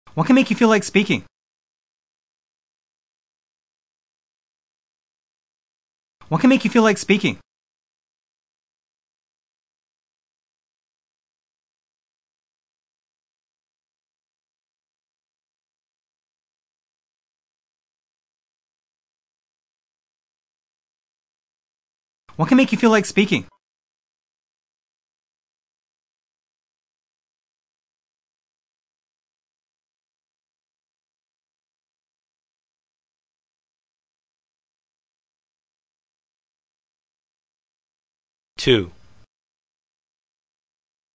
Speech Communication Dictation
Form-Focused Dictation 1: Wh. vs. Yes/No Questions (intonation patterns)